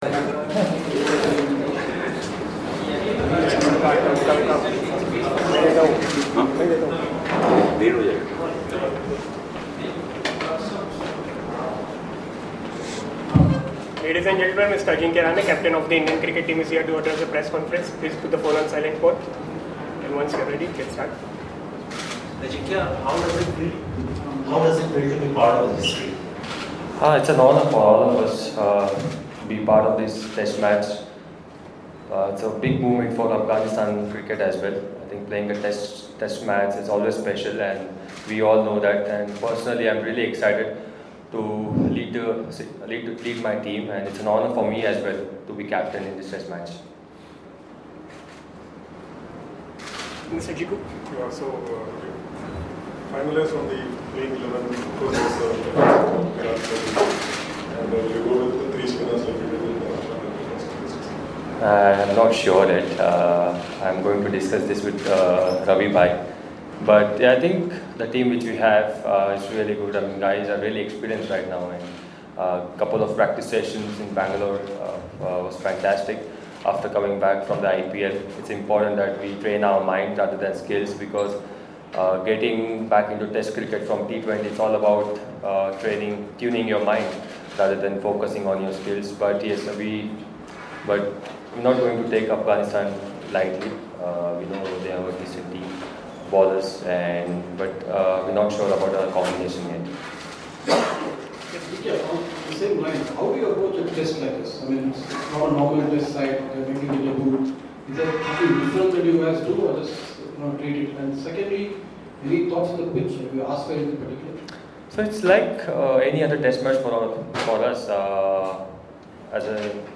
Audio interview with Ajinkya Rahane the Indian Cricket Team captain speaking with the media at the M. Chinnaswamy Stadium, Bengaluru on Wednesday, June 13, 2018 before the Afghanistan test.